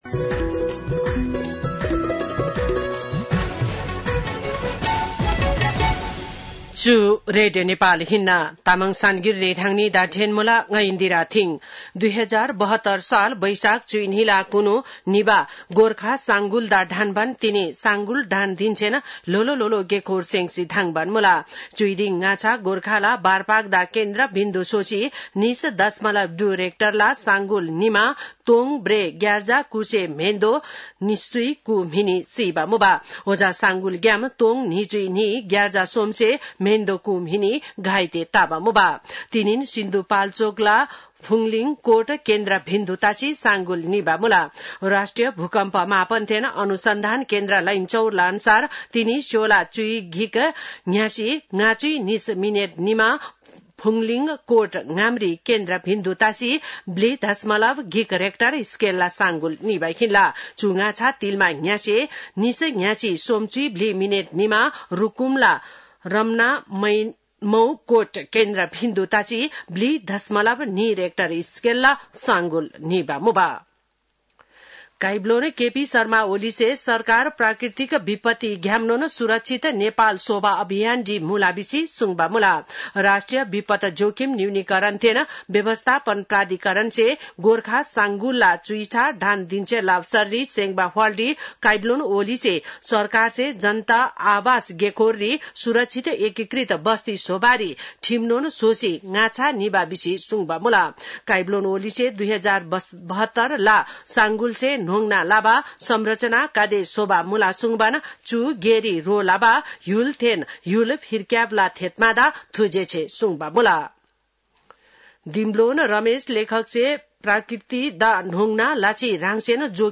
तामाङ भाषाको समाचार : १२ वैशाख , २०८२